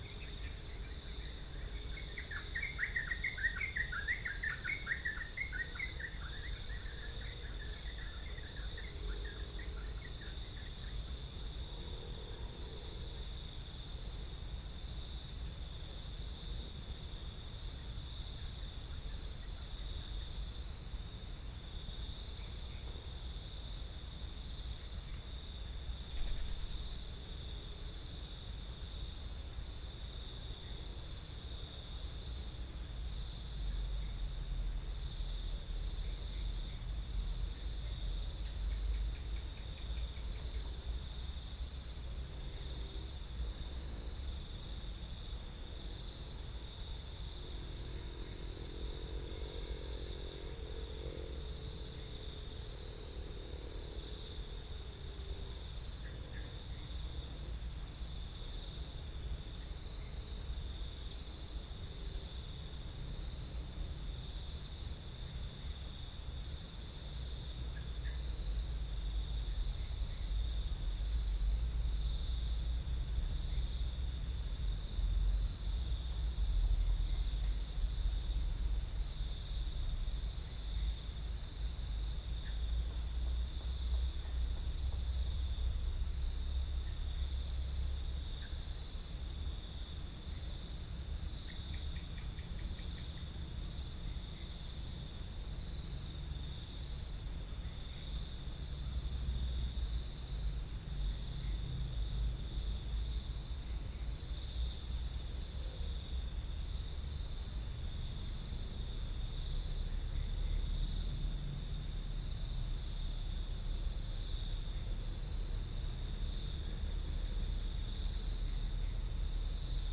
Spilopelia chinensis
Pycnonotus goiavier
Todiramphus chloris